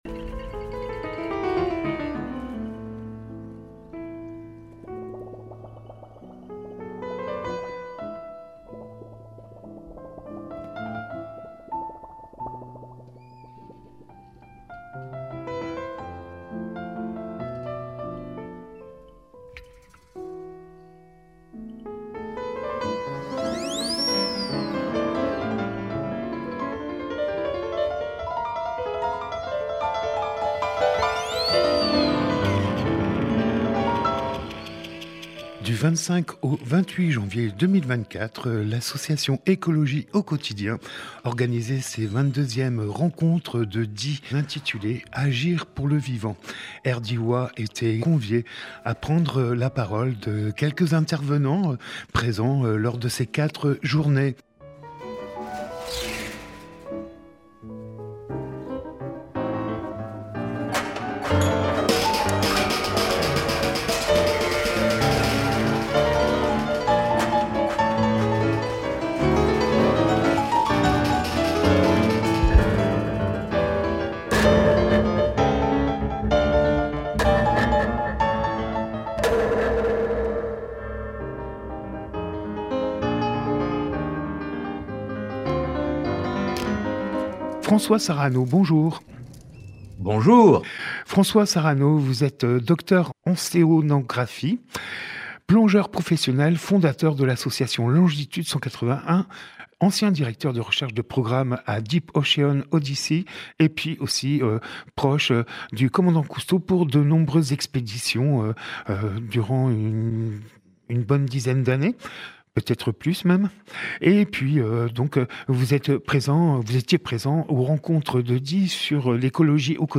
Emission - Interview Agir pour le vivant : L’Océan par François Sarano Publié le 17 février 2024 Partager sur…
Lieu : Studio RDWA